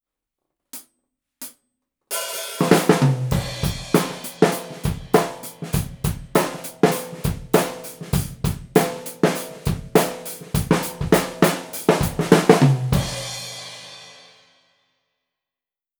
AKG C451B一本のみでドラムを録ってみました。
④ドラマー目線
最後に、ドラマーからの目線の高さからマイキングしてみました。
これはこれでバランス良くていいサウンドですね！